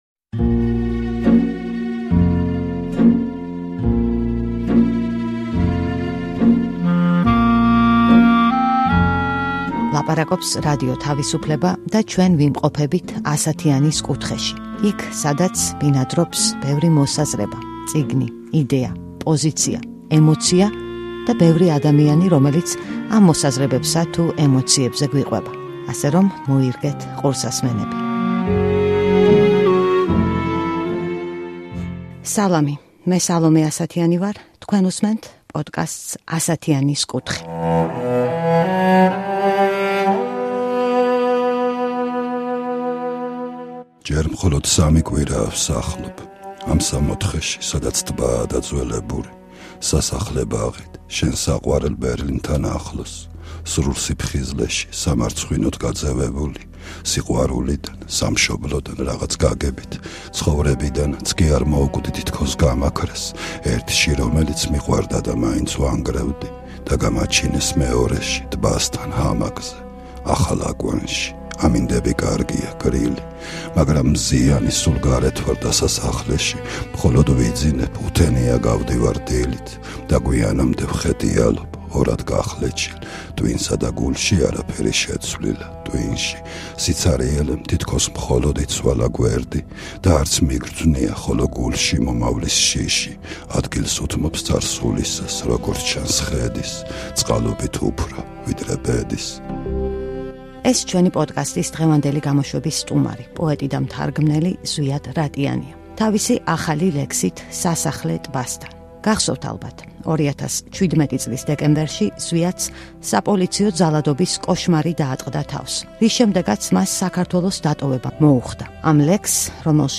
„ასათიანის კუთხეს“ სტუმრობს პოეტი ზვიად რატიანი, რომლის ცხოვრებაც მნიშვნელოვნად შეცვალა საპოლიციო ძალადობასთან შეჯახებამ 2017 წლის მიწურულს. ზვიადი გვიყვება ამ გამოცდილებაზე, გვიკითხავს თავის ახალ, საქართველოდან შორს დაწერილ ლექსს და იხსენებს მისი საეტაპო ტექსტის, „ნეგატივის“ ისტორიას.